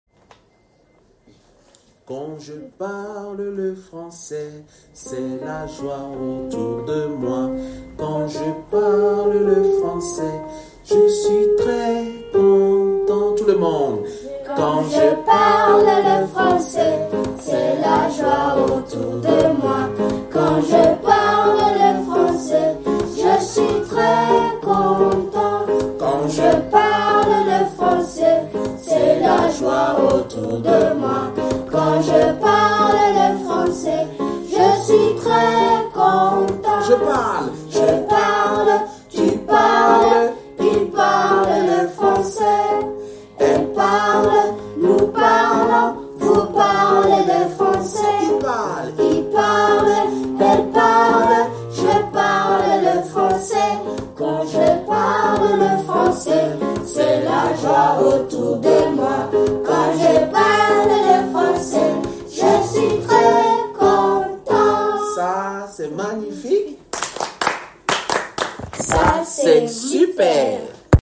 Chanson : Quand je parle le français